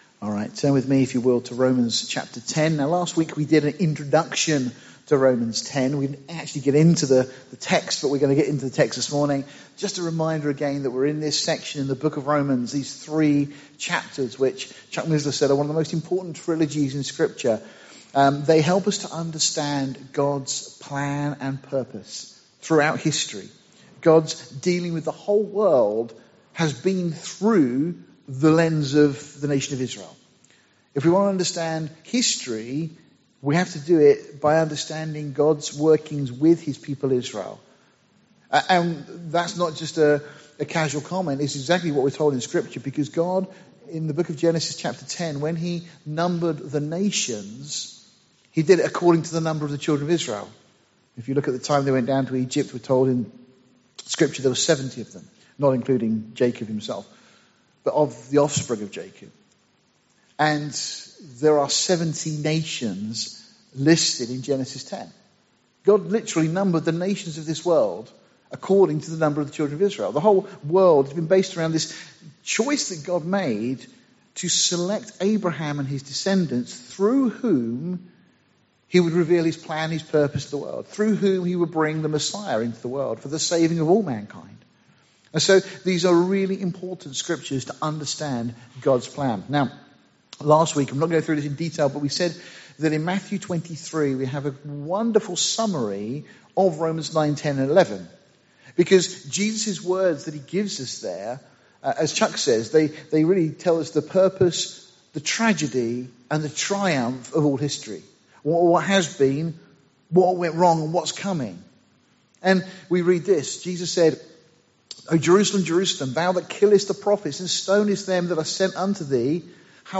Series: Sunday morning studies Tagged with Israel , verse by verse